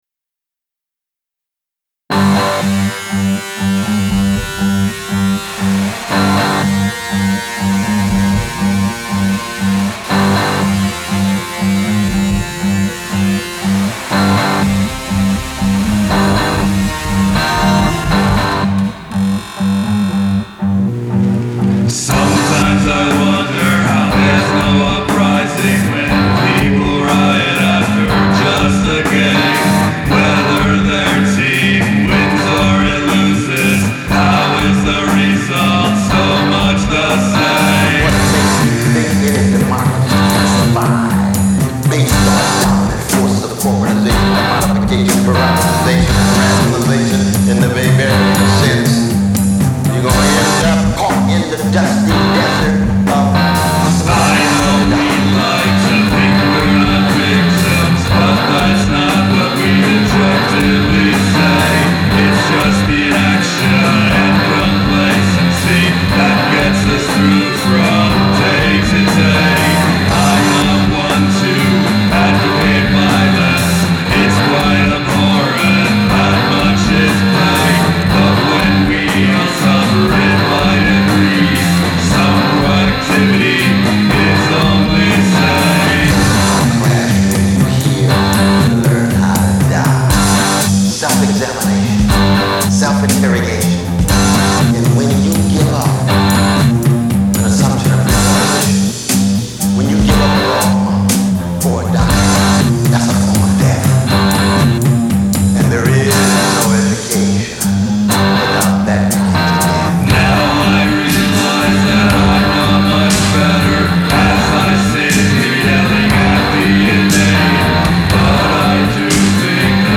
Keyboards and drum machine